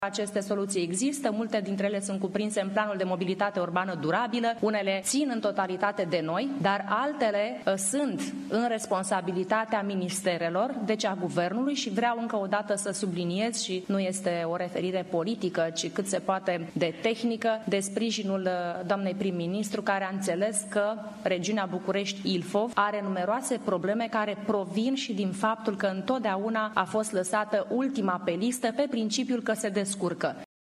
Gabriela Firea a spus că are tot sprijinul pentru proiectele sale din partea premierului Virica Dăncilă:
Declarațiile au fost făcute la seminarul “Împreună pentru dezvoltare durabilă – Dezvoltarea regiunii Bucureşti-Ilfov”.